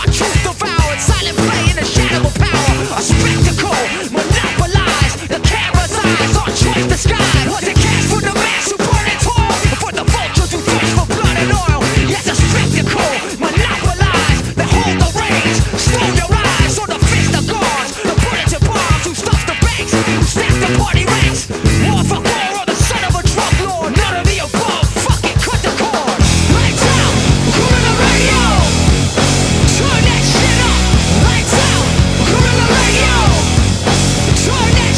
Жанр: Рок / Альтернатива / Метал